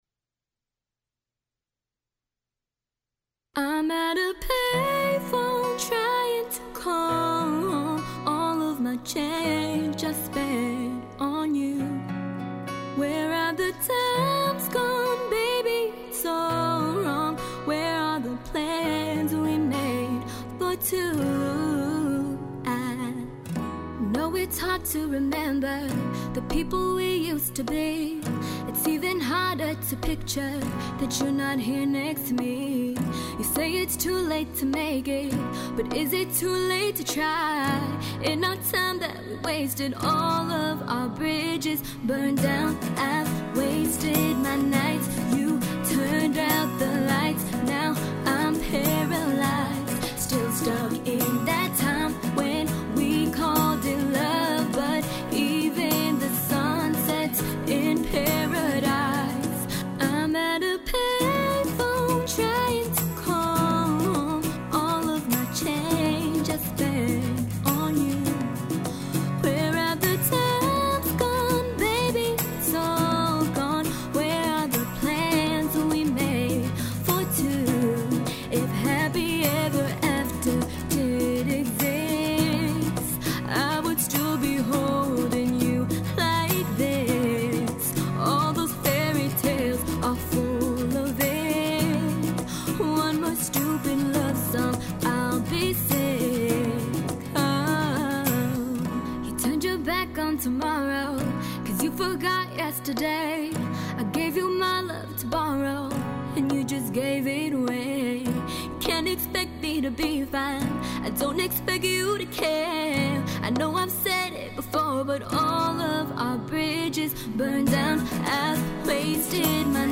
female acoustic version